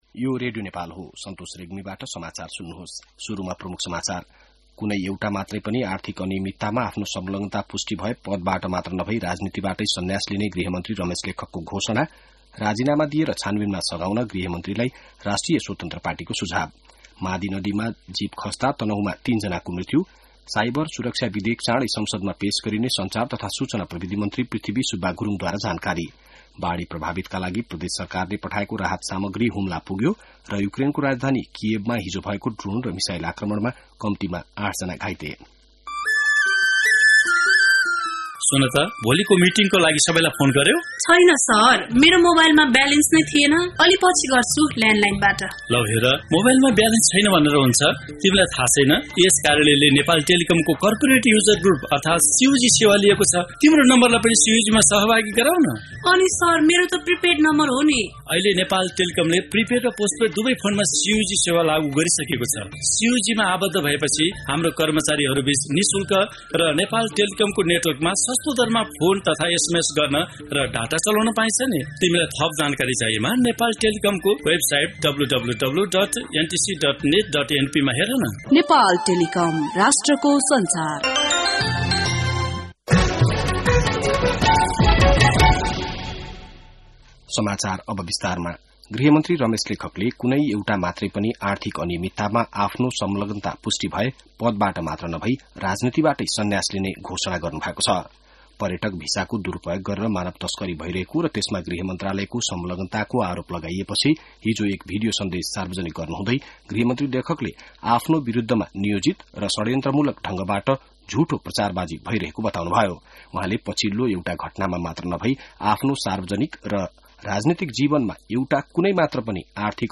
बिहान ७ बजेको नेपाली समाचार : ११ जेठ , २०८२